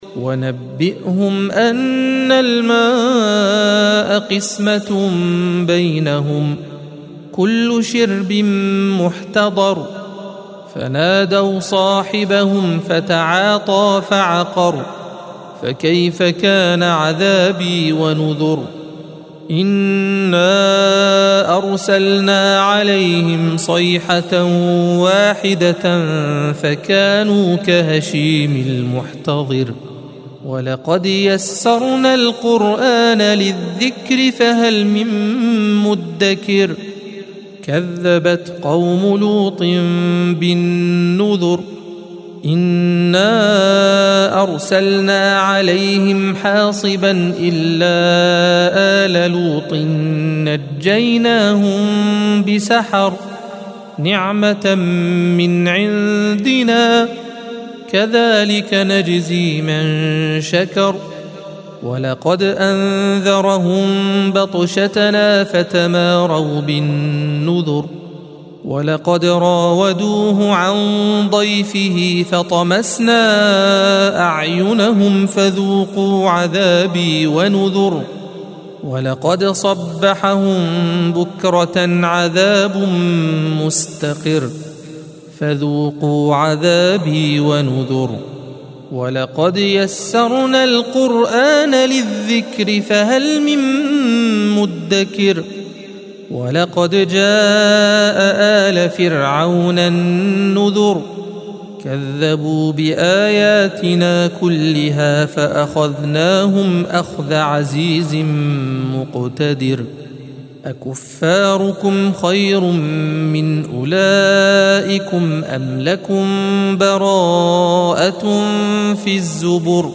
الصفحة 530 - القارئ